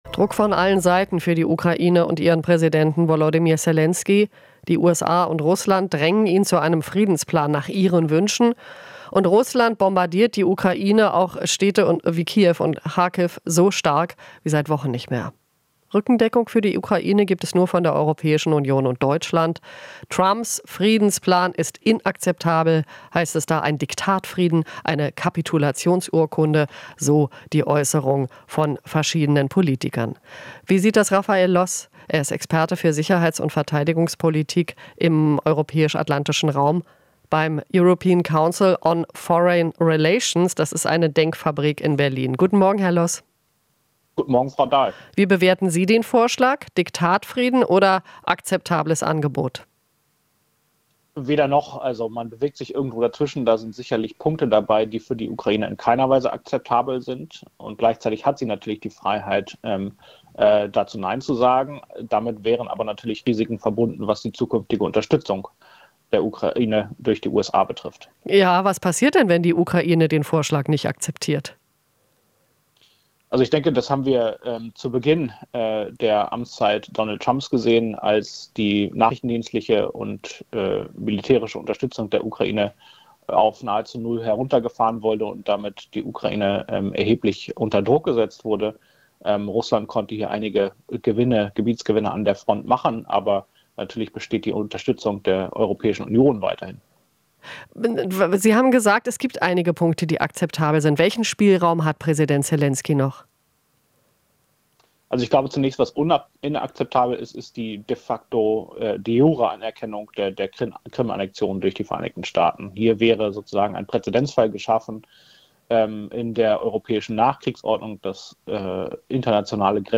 Interview - Sicherheitsexperte: Es geht Trump um den Deal - nicht den Inhalt